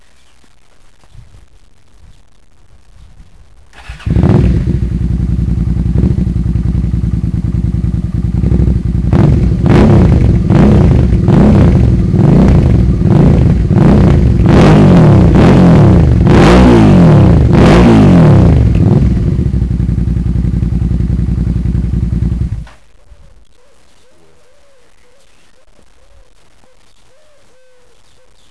Klingt wirklich nett, und erst ohne den db-Killer, aber das mache ich nur auf der Rennstrecke ! (bis jetzt)
offen-Start-Stop, offen-Start-Fahrt-Stop, mit db-Killer Start-Fahrt-Stop
offen2_start_stopwav.wav